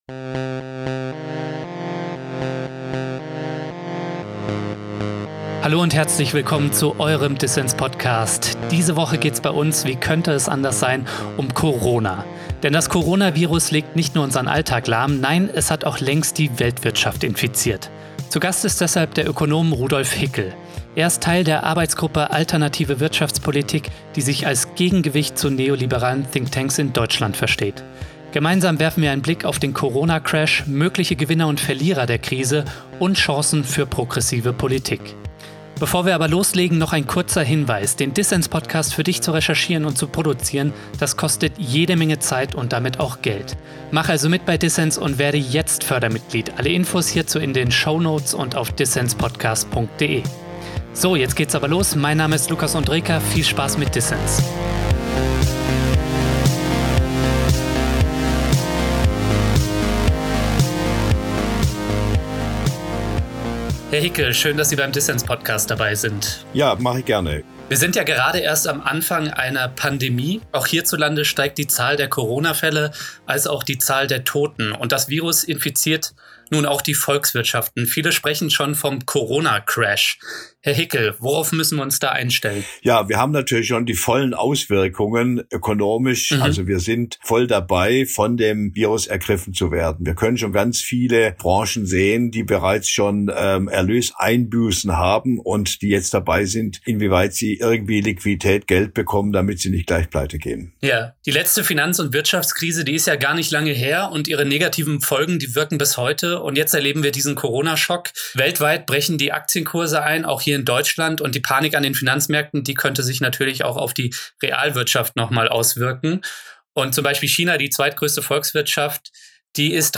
Rettungsmaßnahmen für Konzerne dürften aber nicht auf Kosten der Gesellschaft gehen. Ein Gespräch über die sozialen und ökologischen Folgen der Krise, Sparpolitik in Krankenhäusern und Corona-Parties.